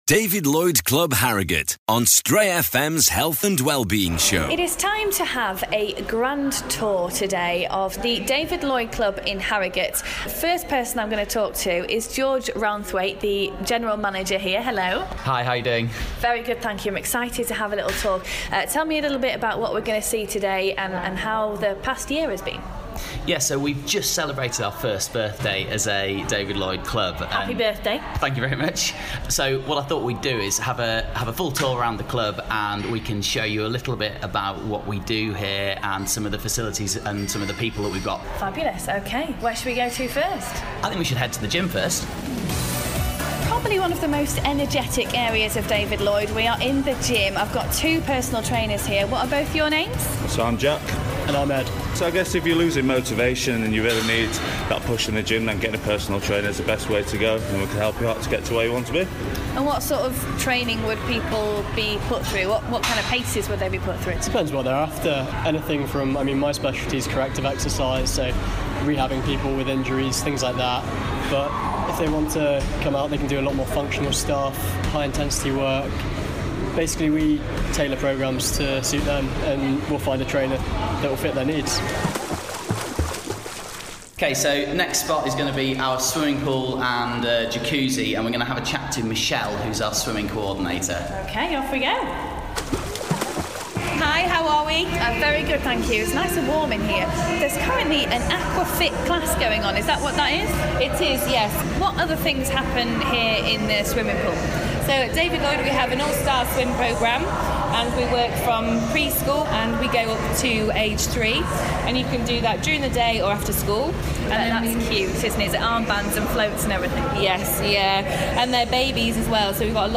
Tennis, spa, gym, classes, a creche for the kids, cafe and swimming… the full package! Tonight we take a tour of the David Lloyd Club in Harrogate a year after it opened.